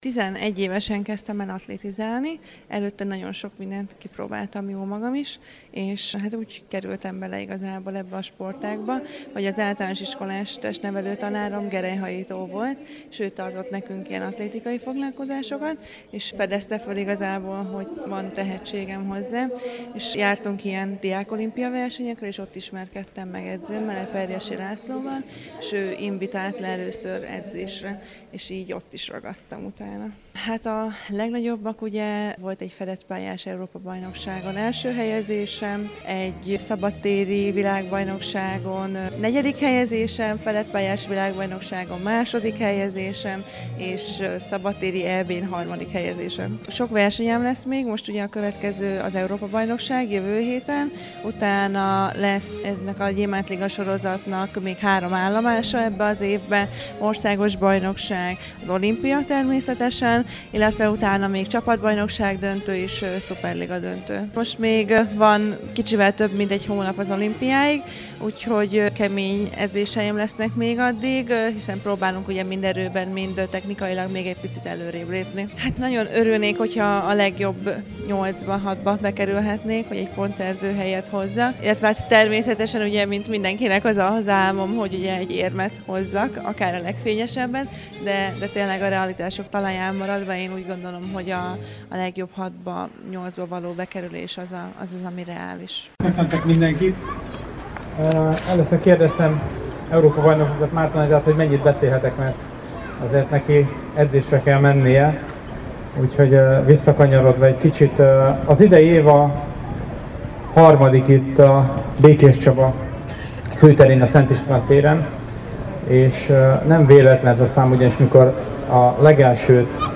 Márton Anita fedett pályás Európa-bajnok magyar súlylökő volt a Sporthétfő rendezvény vendége a Szent István téren.